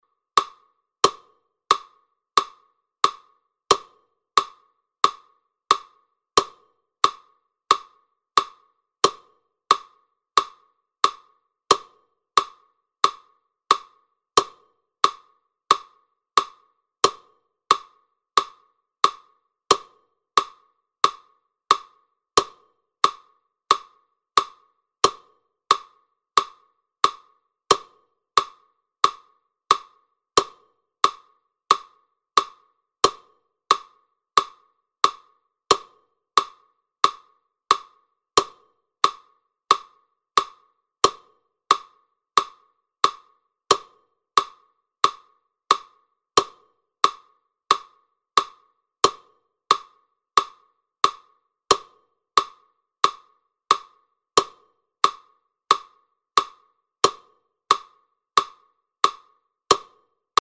Звуки метронома
90 ударов в минуту